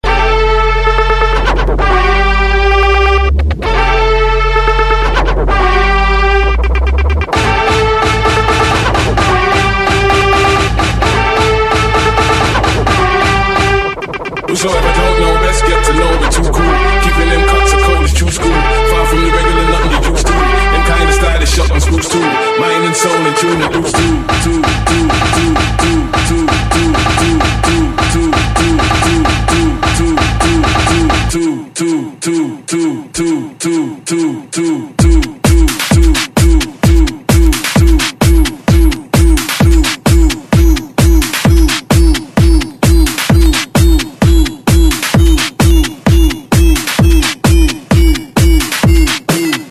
Назад в Рингтоны(нарезка mp3)
Нравится клубняк тогда качай.